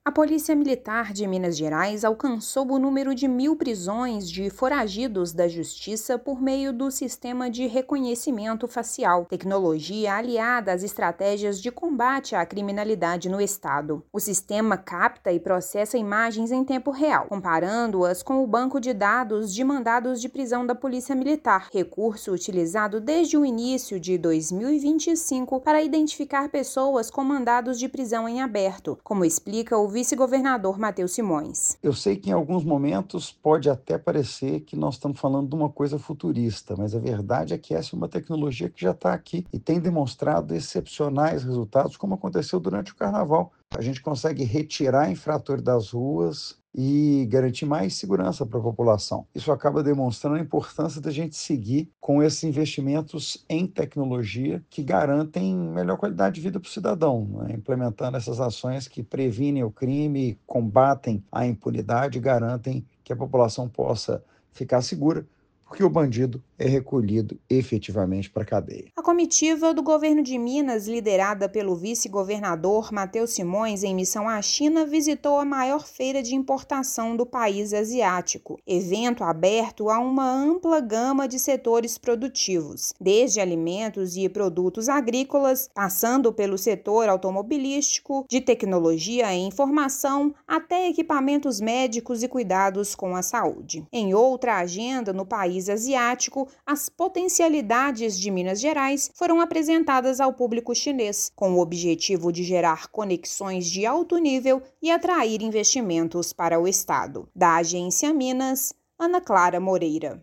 Minuto Agência Minas da semana destaca marca de mil prisões feitas pela Polícia Militar a partir da identificação por reconhecimento facial, além das agendas da comitiva liderada pelo vice-governador Mateus Simões na China. Ouça matéria de rádio.